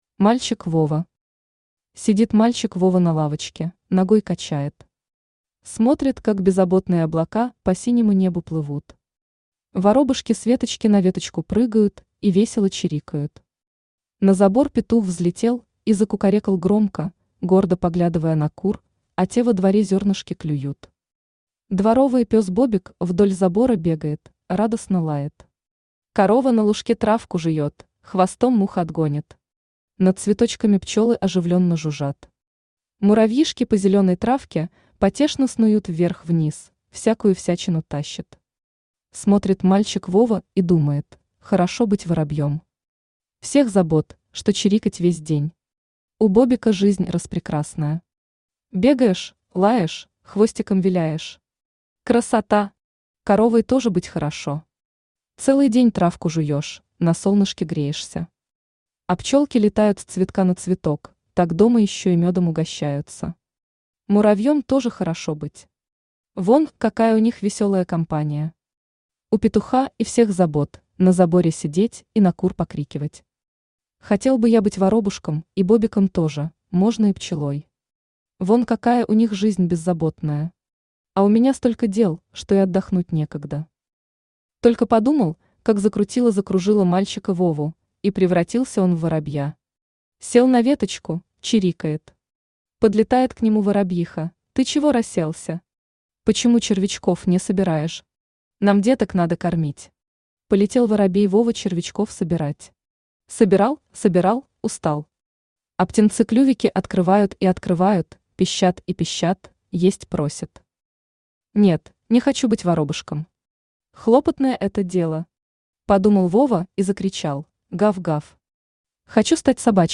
Aудиокнига Три истории Автор Ольга Владимировна Манько Читает аудиокнигу Авточтец ЛитРес.